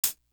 Desire Hat.wav